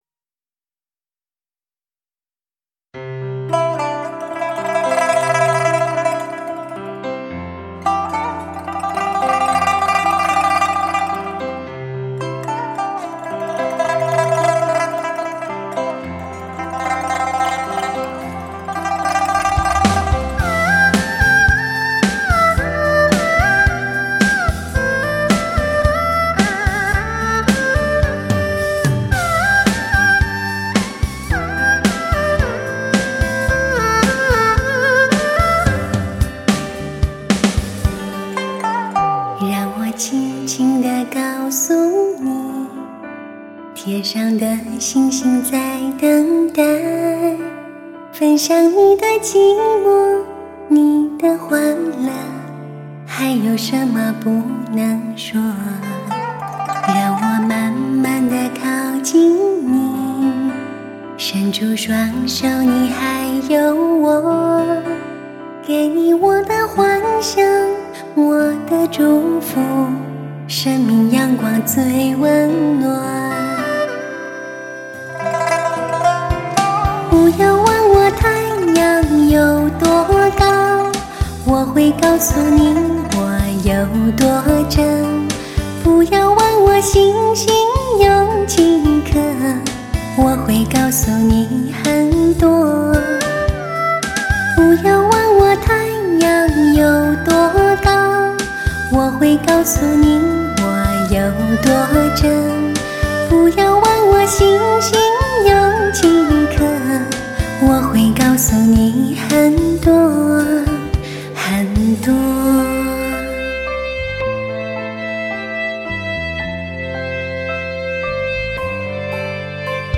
精选各大音乐排行榜最流行的发烧情歌